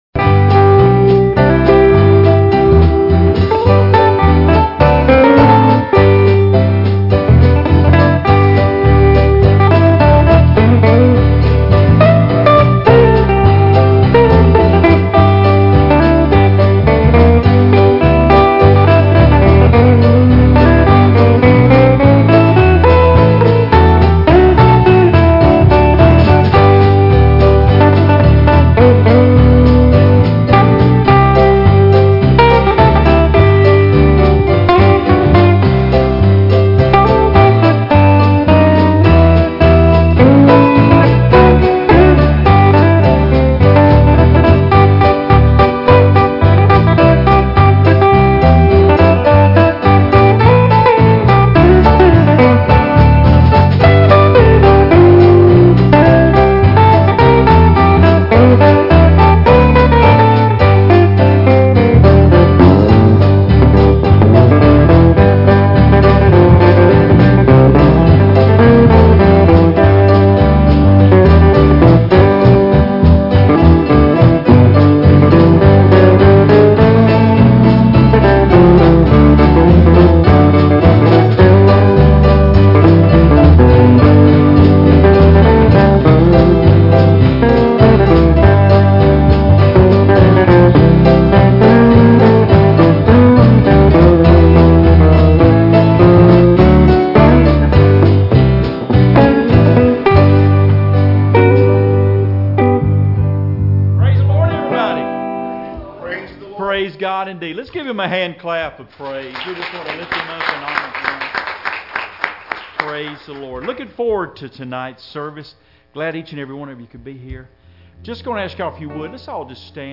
Night of Singing